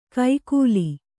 ♪ kaikūli